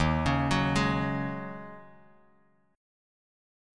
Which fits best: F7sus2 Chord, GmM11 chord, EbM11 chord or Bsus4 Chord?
EbM11 chord